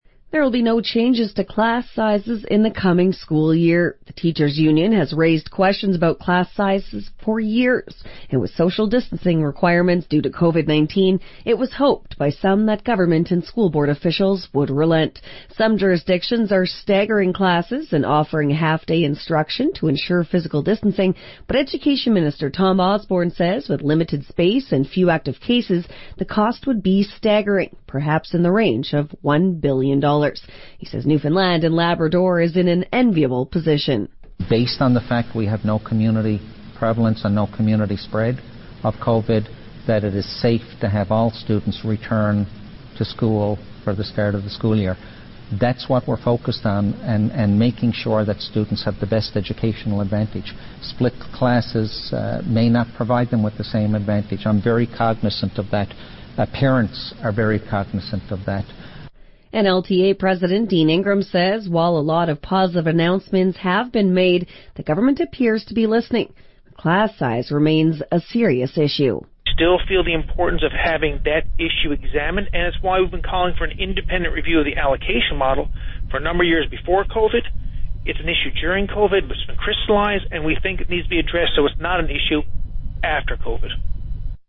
Media Interview - VOCM 6am News Sept 4, 2020